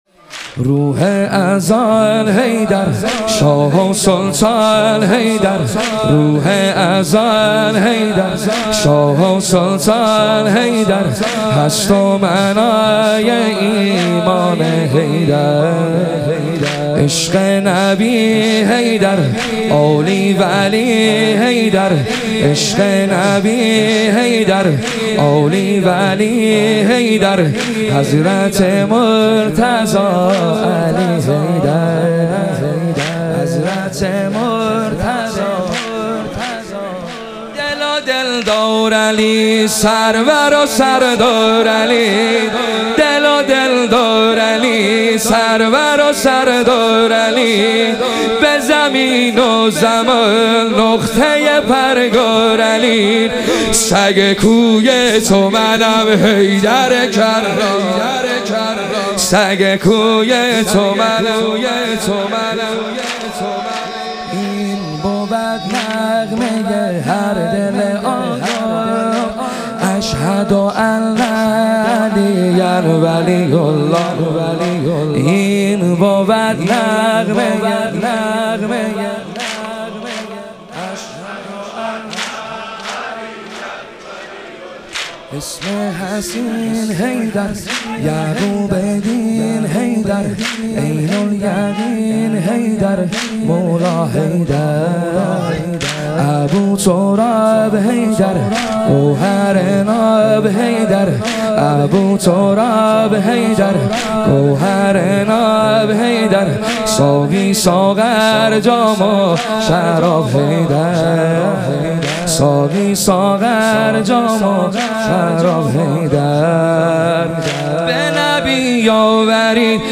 ایام فاطمیه دوم - واحد - 10 - 1403